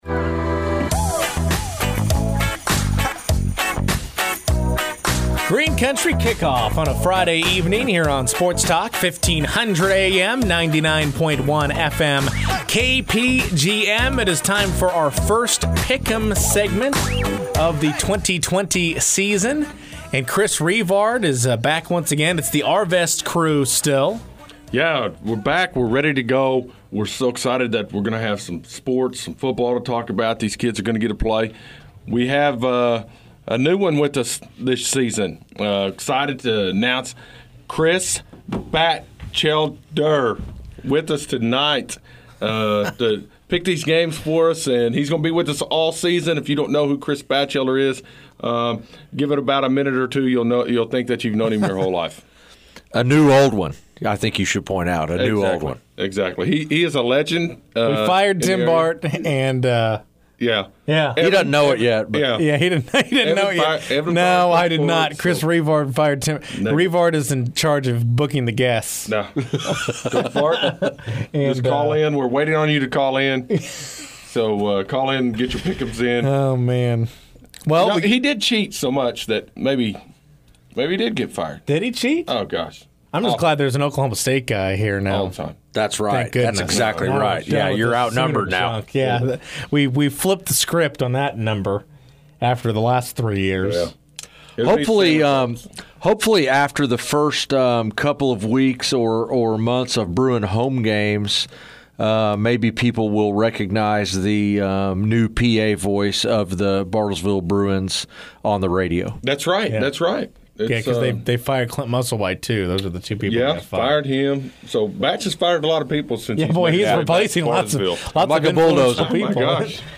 We will preview the games on The Green Country Kickoff Show, which airs from 5:00-6:00 PM on Sports Talk 99.1 FM - KPGM. The show will feature picks, predicitions and interviews with area head coaches.